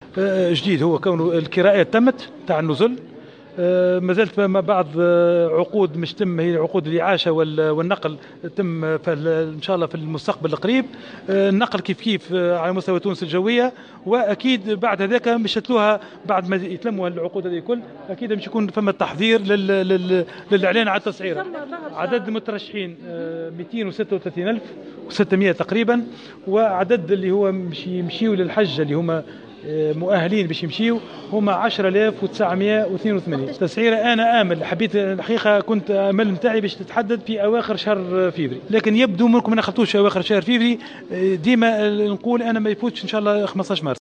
أكد وزير الشؤون الدينية أحمد عظوم، خلال ندوة صحفية للتعريف بميثاق الأئمة، أنه تم كراء النزل الذي ستستقبل حجيج 2019.